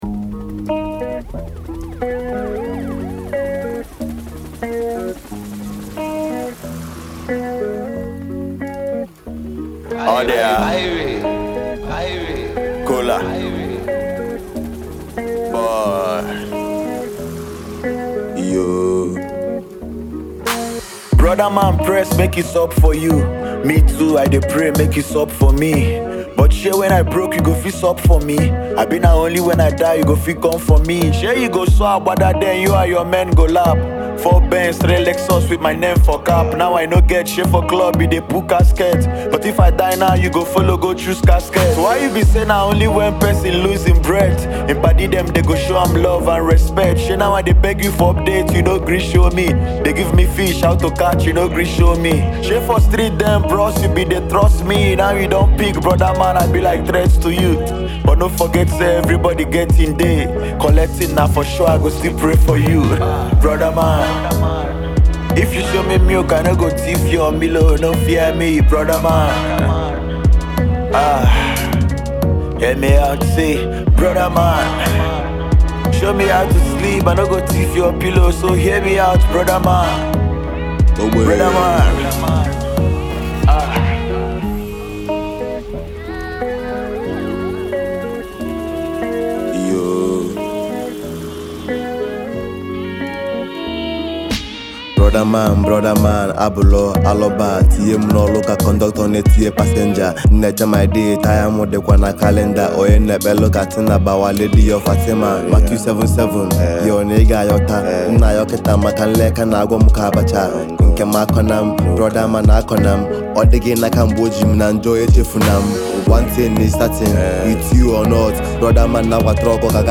Indigenous Igbo rap prodigy